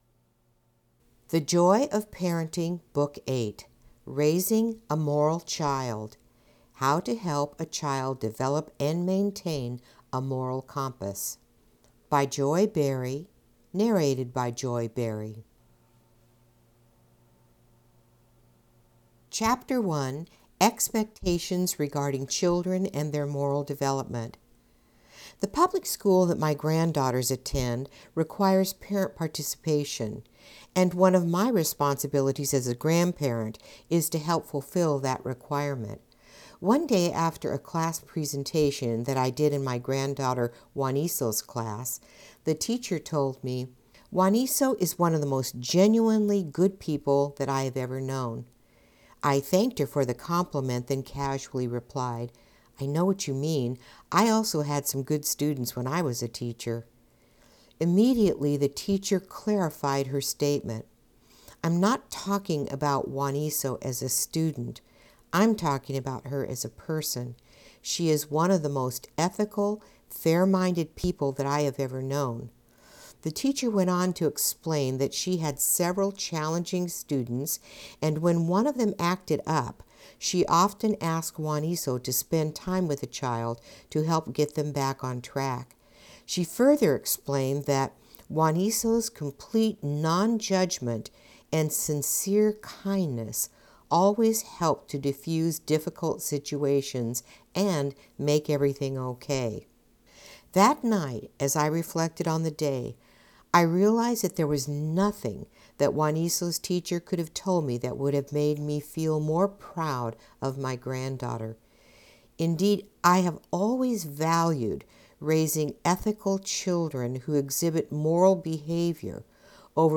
AUDIO BOOK ABOUT CHILDREN AND MORALITY FOR PARENTS